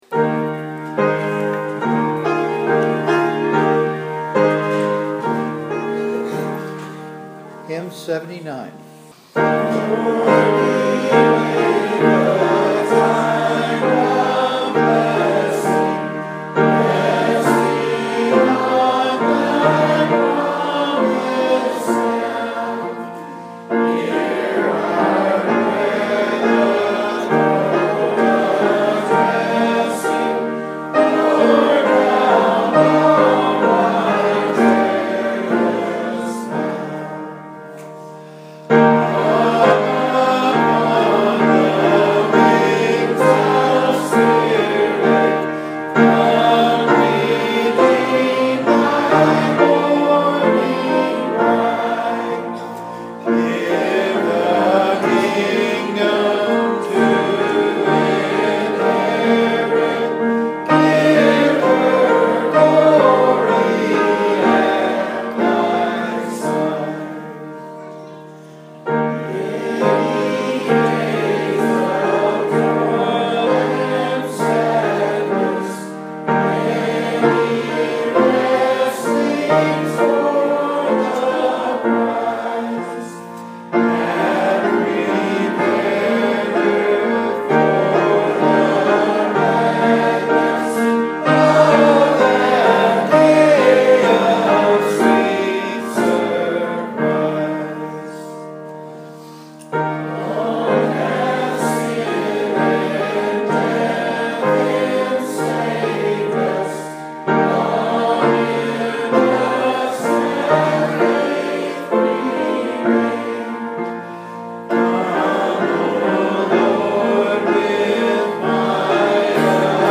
Quarterly gatherings are held at the Hye campgrounds in November, February, and May each year.
Hymns Recorded: